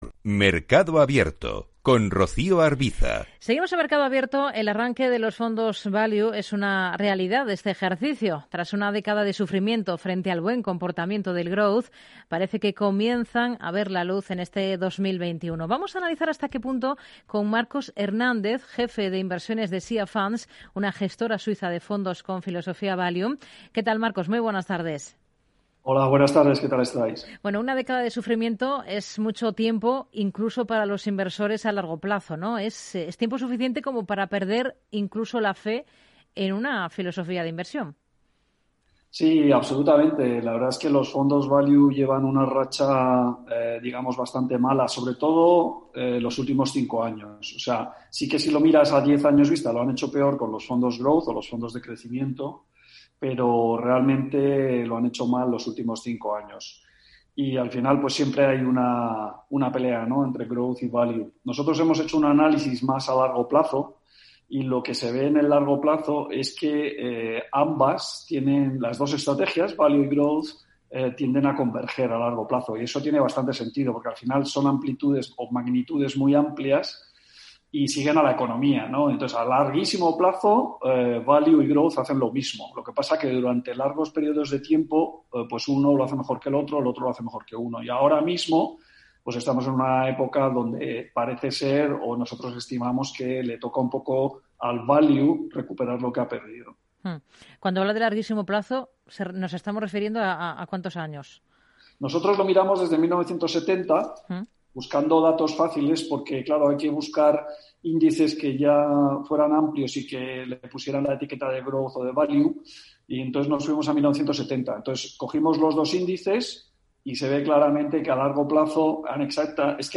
Escuchar la entrevista en Capital Radio aquí.